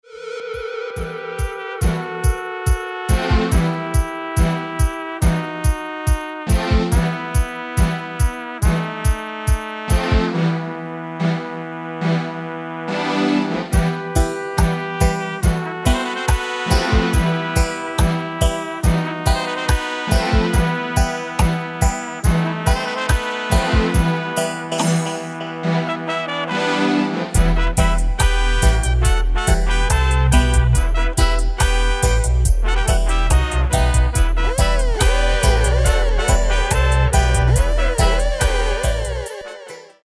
an accelerating horns version and the dub.